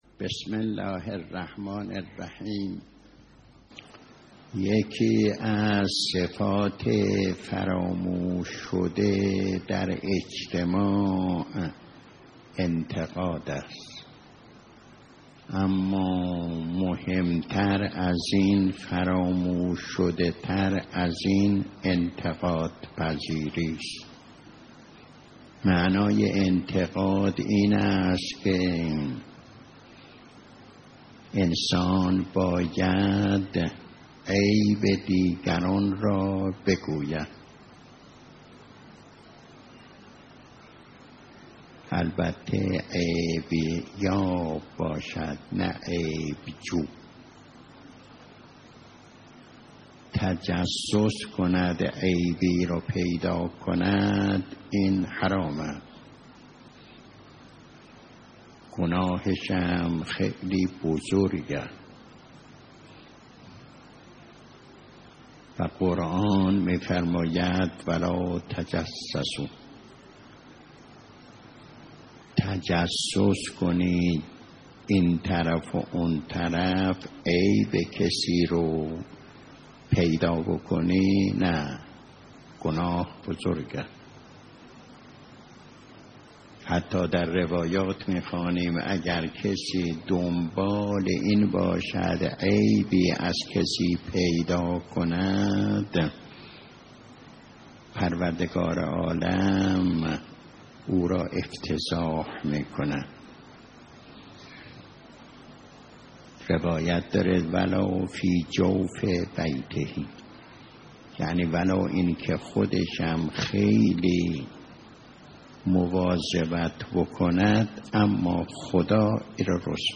آیت الله مظاهری در یکی از جلسات اخلاق خود، این دو مفموم را به‌ویژه در آموزه‌های دینی به عنوان ابزاری برای اصلاح و رشد فردی و اجتماعی معرفی کرده اند که متن بررسی این موضوع تقدیم شما فرهیختگان می شود.